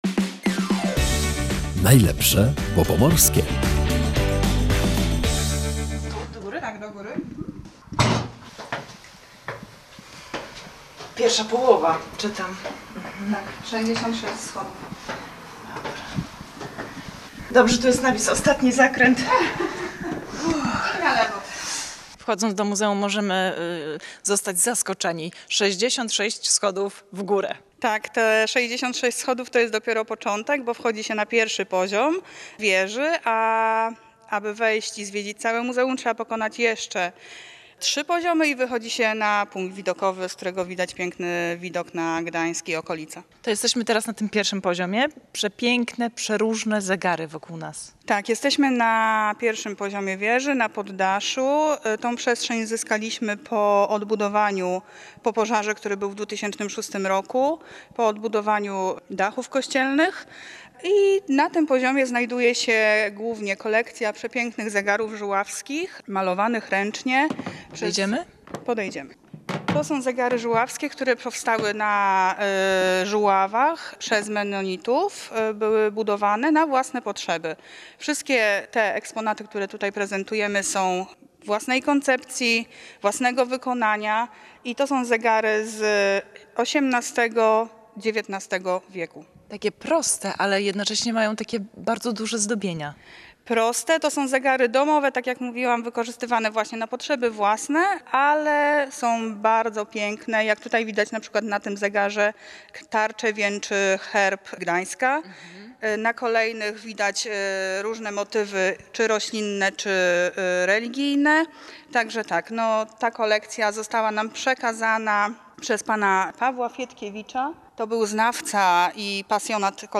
W audycji „Najlepsze, bo pomorskie” zabieramy słuchaczy do Muzeum Nauki Gdańskiej. To niezwykłe miejsce mieści się w wieży kościoła św. Katarzyny w Gdańsku.